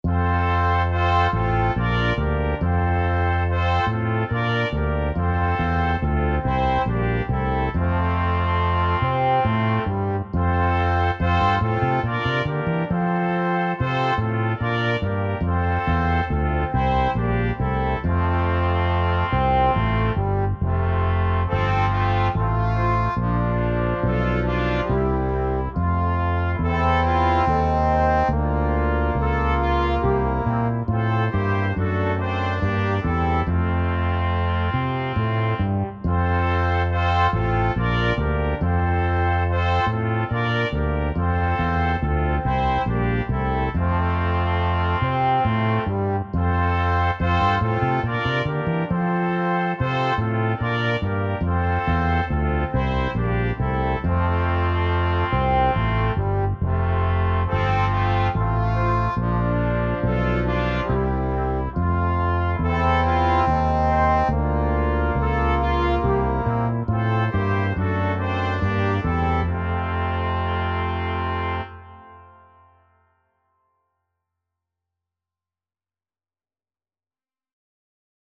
Dychové kvinteto Značky: Inštrumentalne
Vianočné koledy a piesne Zdieľajte na